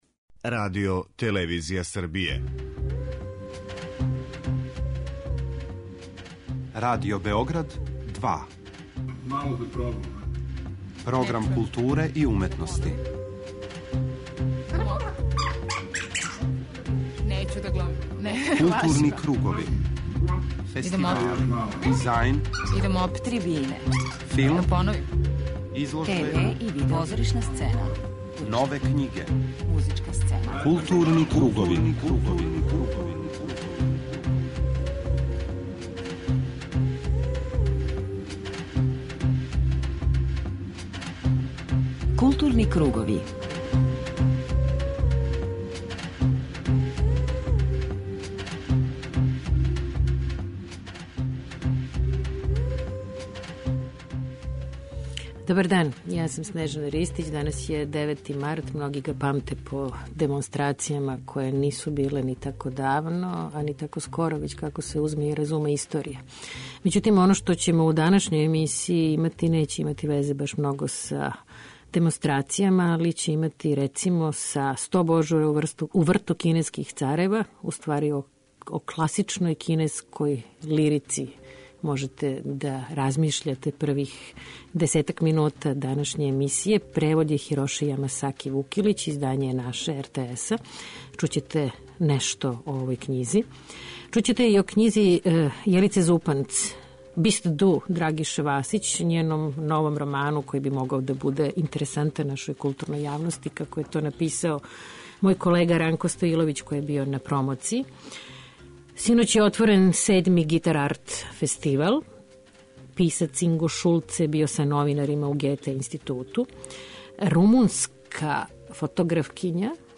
преузми : 41.10 MB Културни кругови Autor: Група аутора Централна културно-уметничка емисија Радио Београда 2.